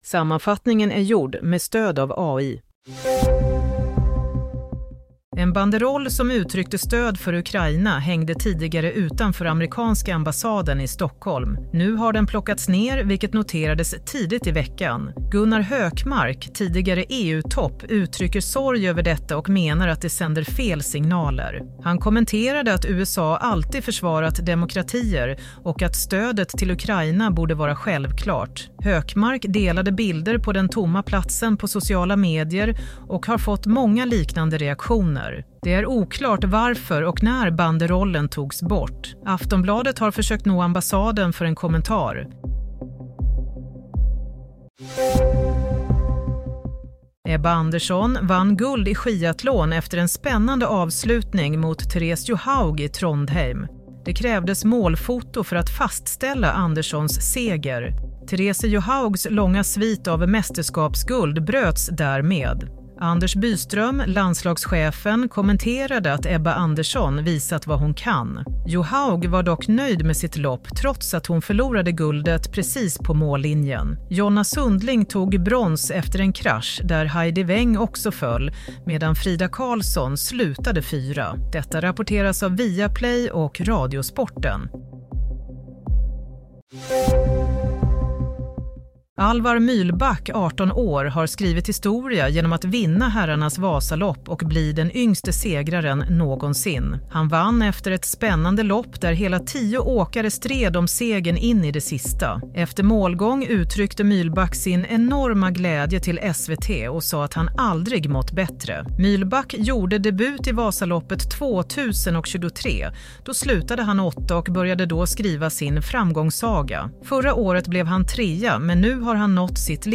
Nyhetssammanfattning - 2 mars 15:30